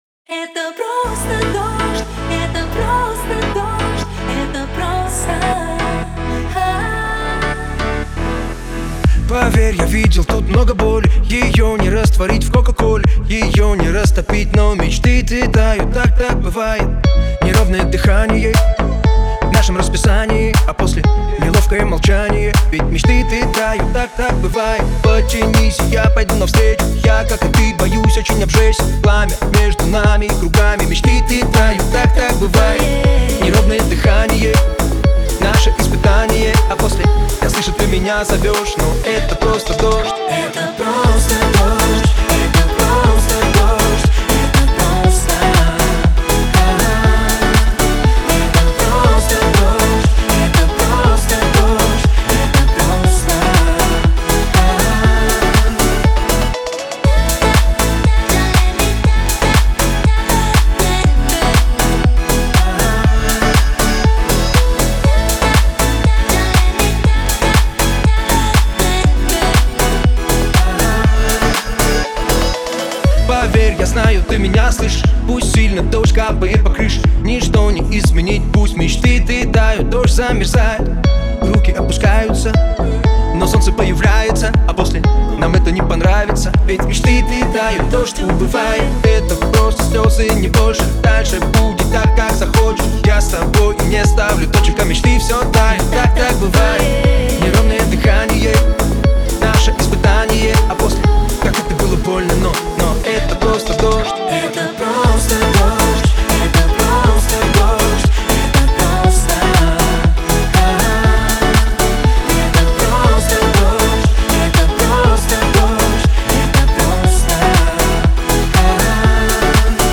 где нежные вокалы переплетаются с ритмичными битами